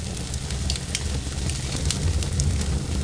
SFX
1 channel
FIRELP9.mp3